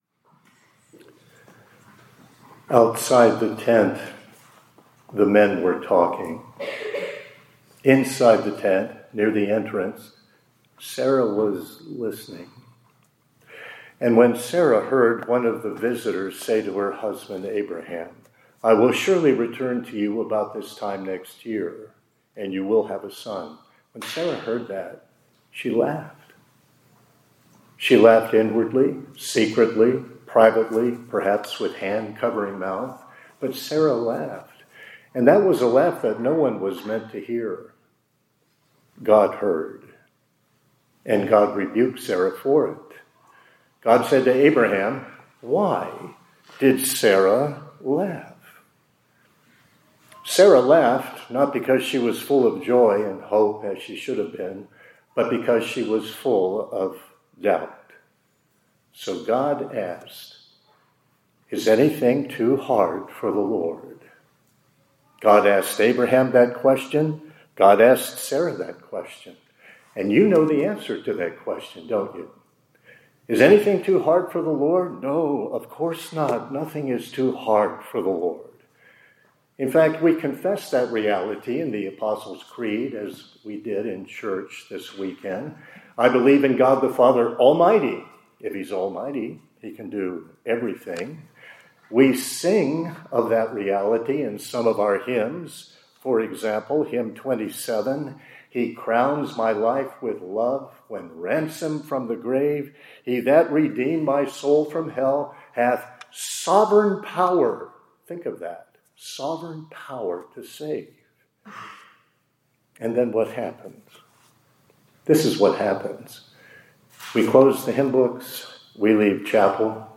2025-10-20 ILC Chapel — Is Anything Too Hard for…